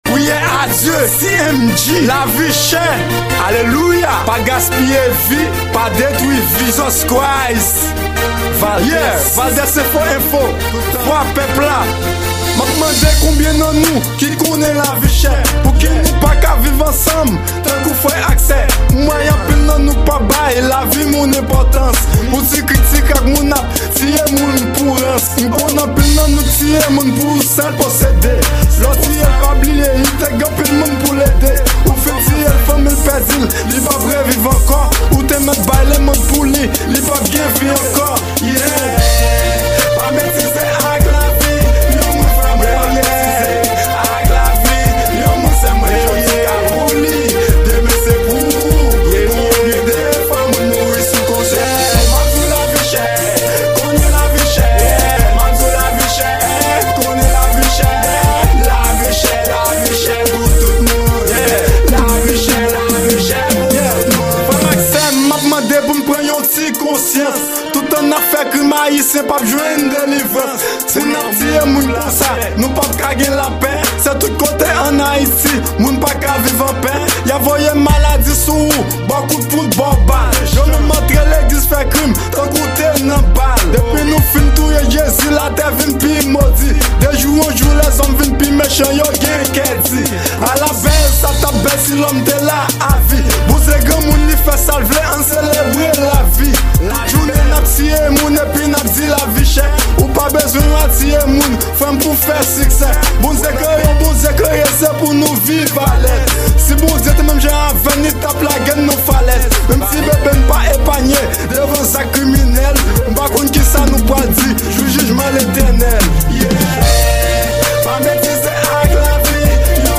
Genre: RAP KREYOL.